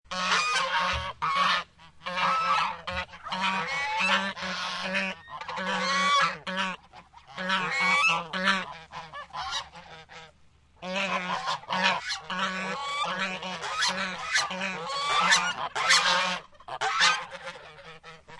Geese Honking Bouton sonore